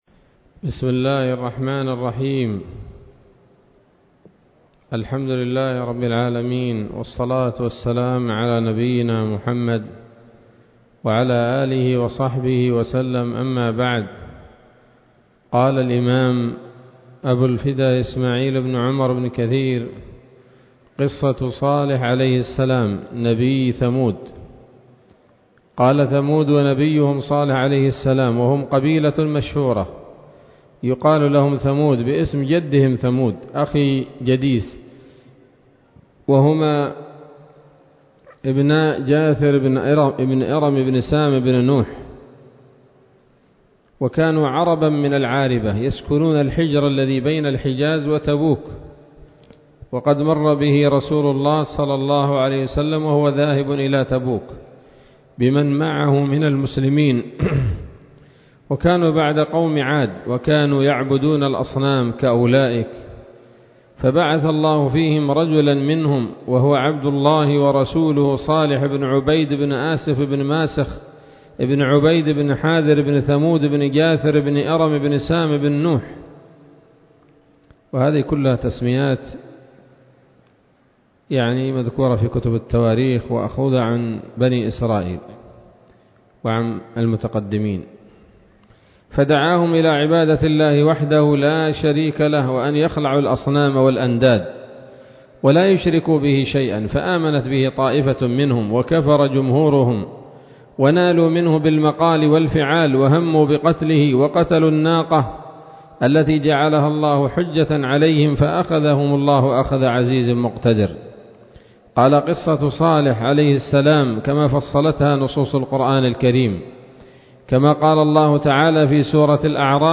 الدرس الرابع والثلاثون من قصص الأنبياء لابن كثير رحمه الله تعالى